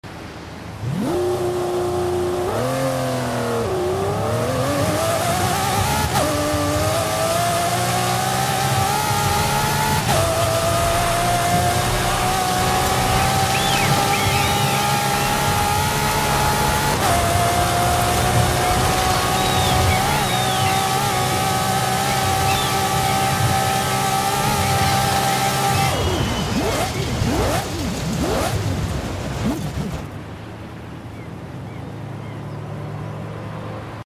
2010 Lamborghini Murcielago LP 670-4